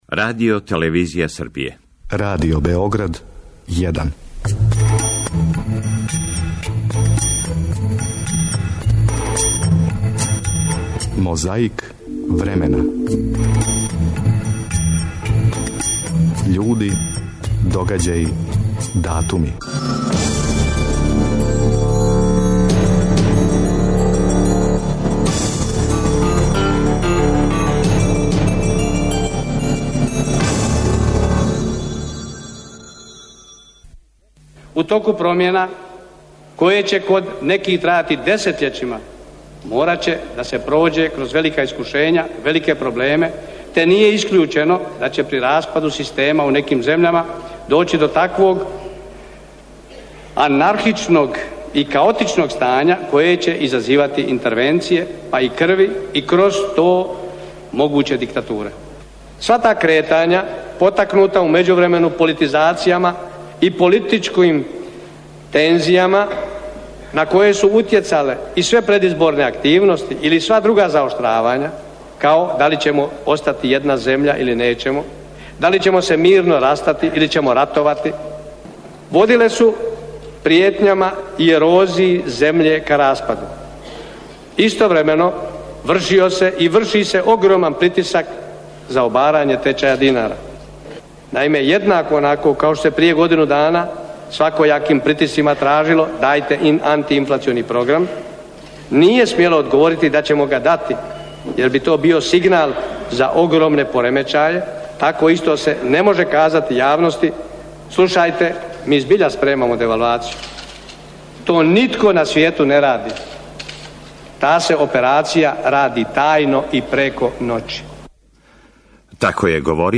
Чућете шта је говорио у Скупштини СФРЈ, у граду Београду, када је у својству председника СИВ-а поднео експозе.
Фронт код Окучана. Екипа радија Новог Сада и ратни извештачи разговарају са борцима.